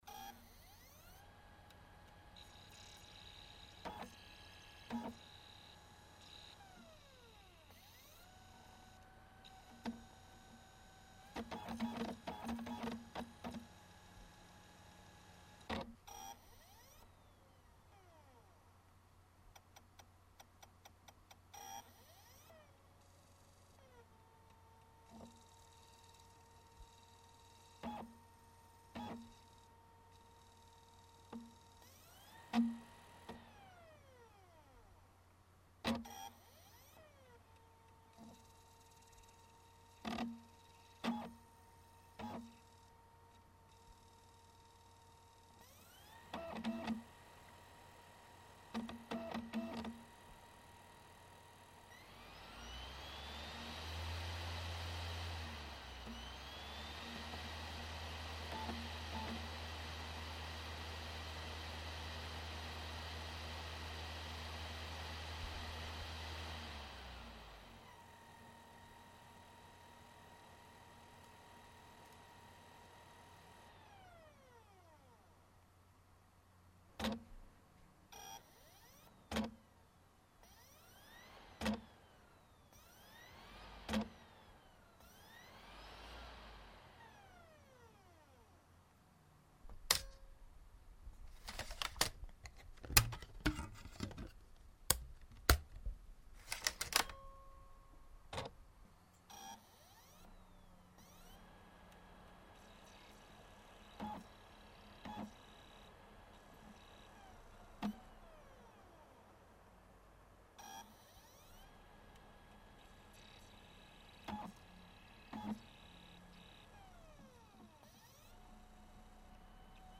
Inside a CD ram